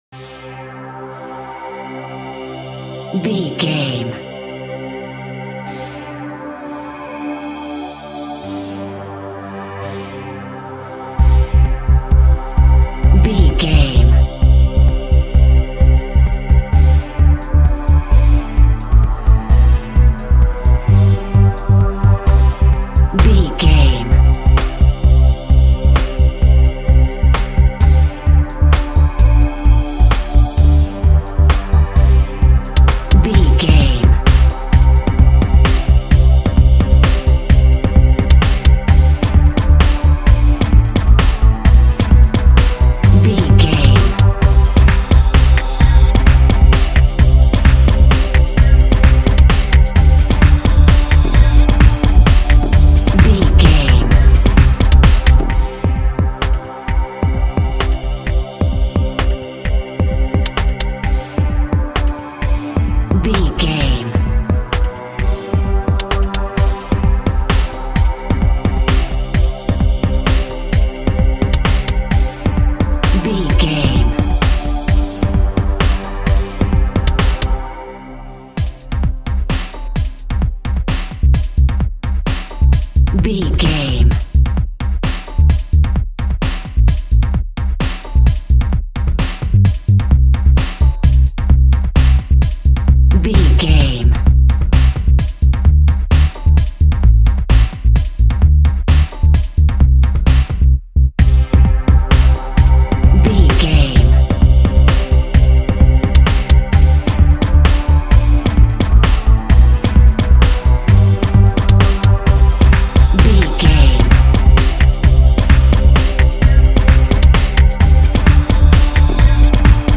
Aeolian/Minor
melancholy
hypnotic
industrial
dreamy
tranquil
drum machine
synthesiser